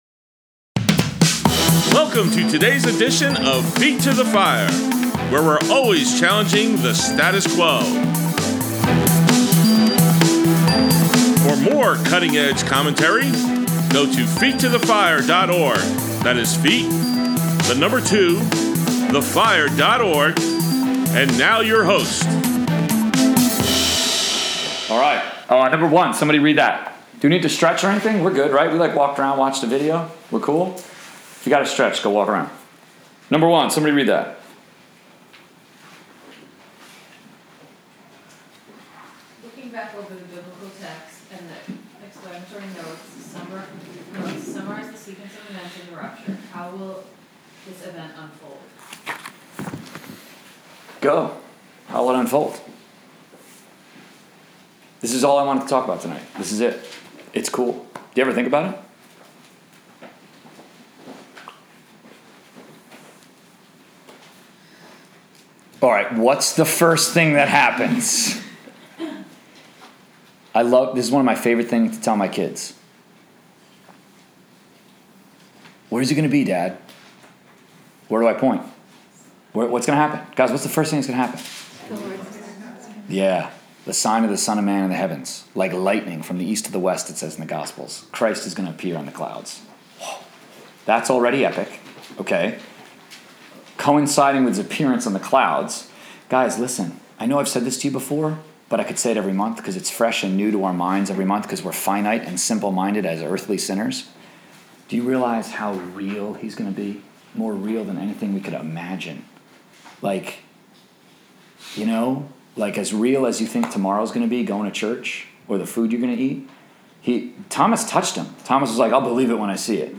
College/Career Bible Study, May 6, 2017: Part 2 of 2